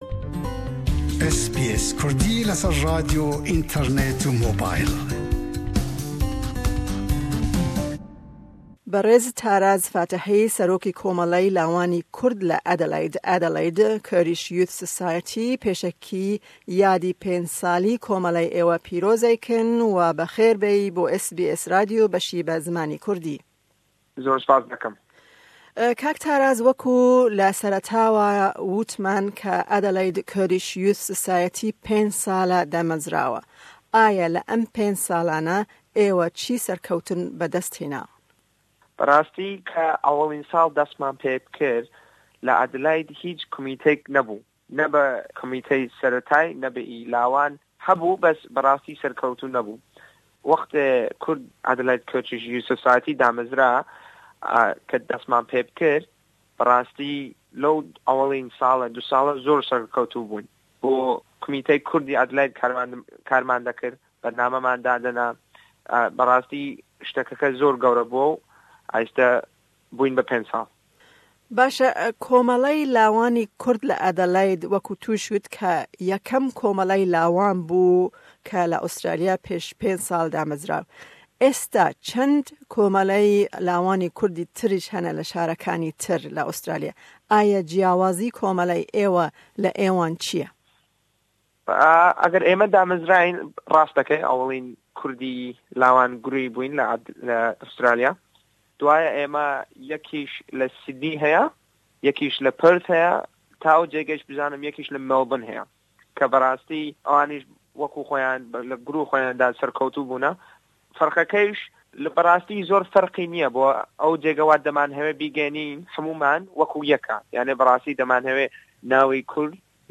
Me derbarê demezrandina pênc saliya komeleyê û serkeftinên ku wan di van pênc salan de bi dest xistî hevpeyvînek pêk anî.